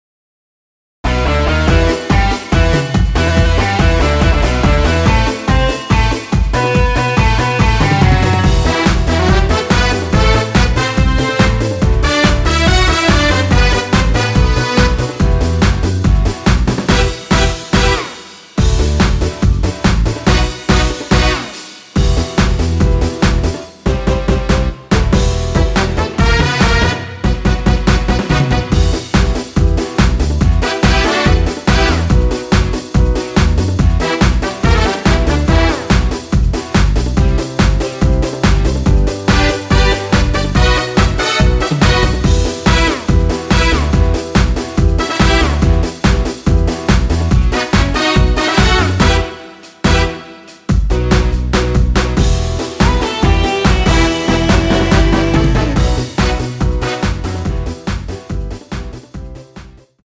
Em
◈ 곡명 옆 (-1)은 반음 내림, (+1)은 반음 올림 입니다.
앞부분30초, 뒷부분30초씩 편집해서 올려 드리고 있습니다.
중간에 음이 끈어지고 다시 나오는 이유는